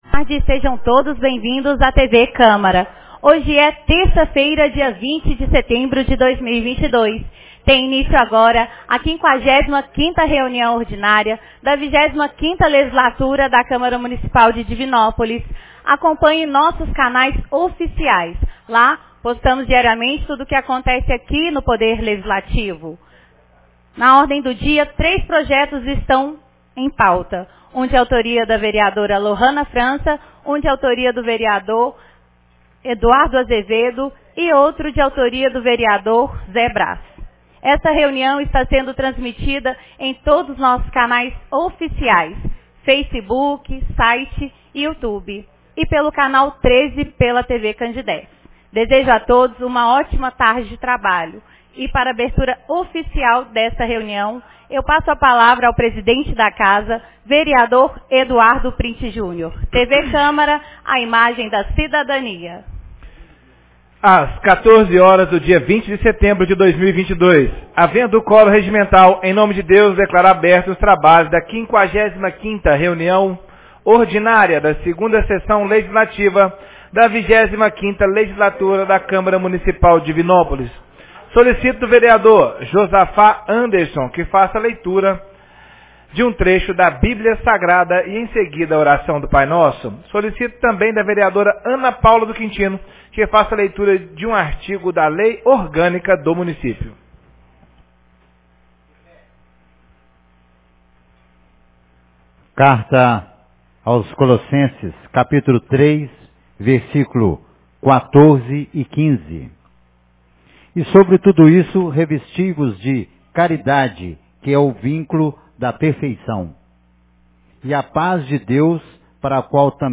55ª Reunião Ordinária 20 de setembro de 2022